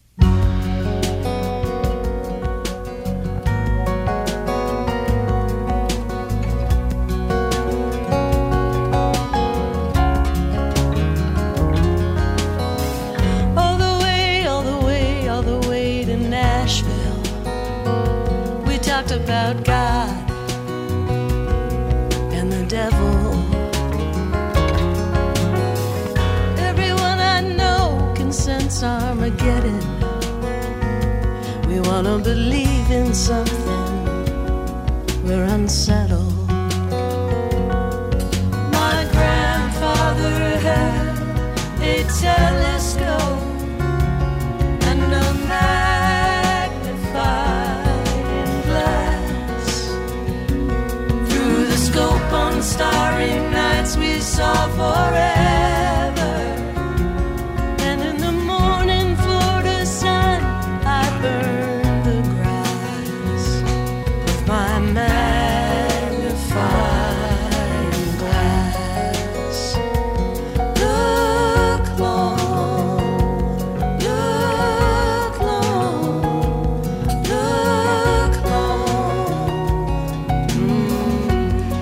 (captured from the webstream)